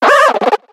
Cri de Flotoutan dans Pokémon X et Y.